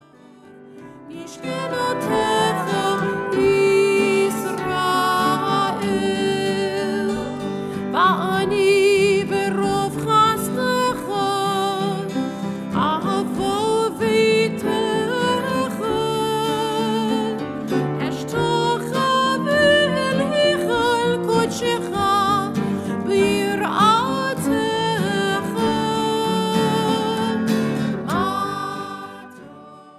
adult choir, instrumental ensemble